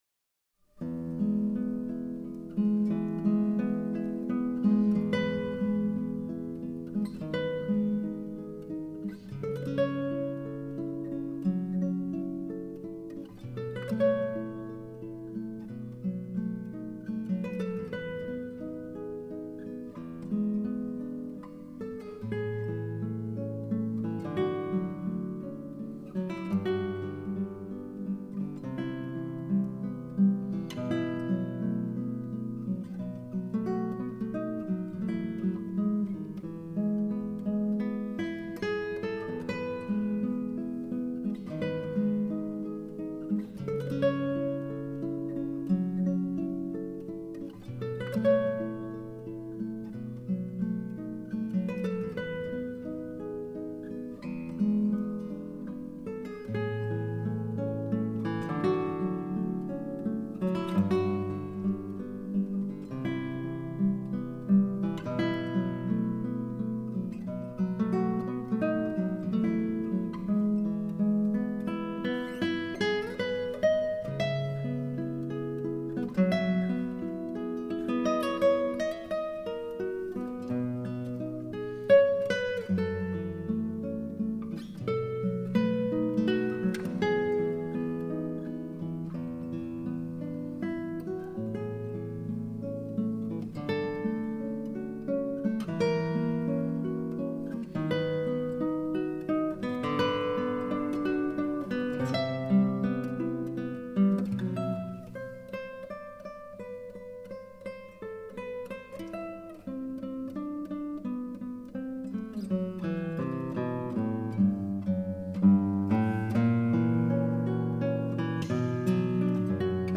【古典吉他】
专辑风格：吉他、独奏